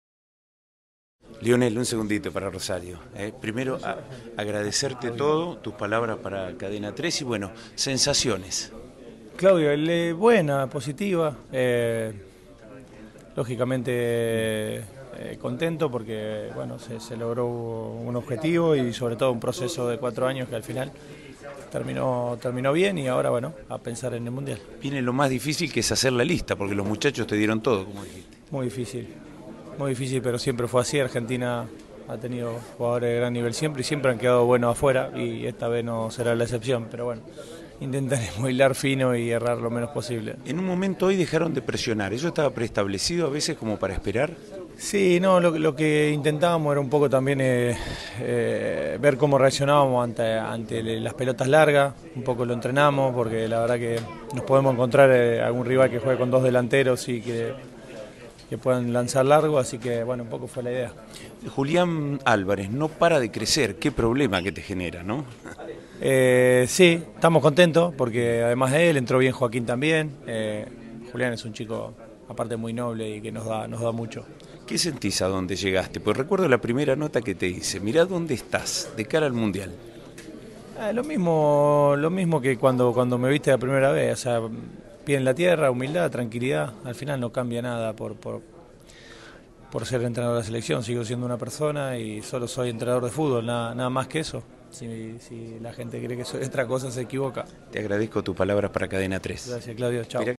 entrevista exclusiva